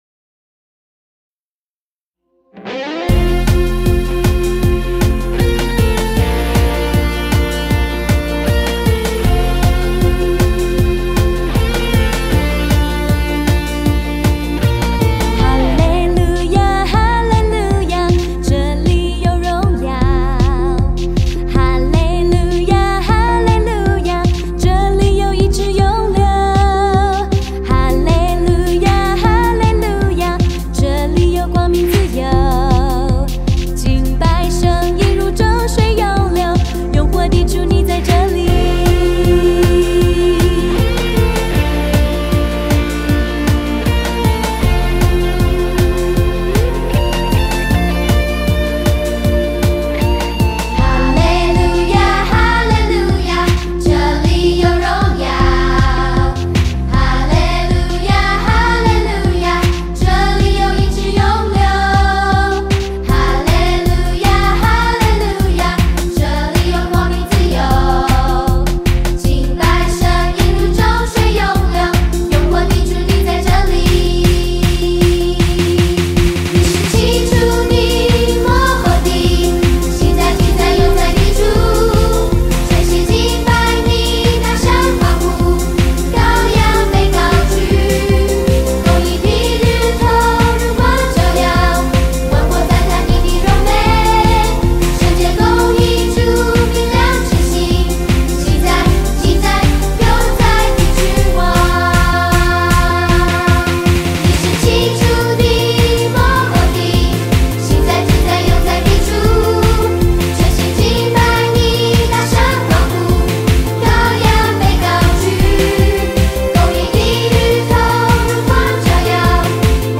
视频里有动作演示，音频里歌会自动重复三遍。
诗歌第一遍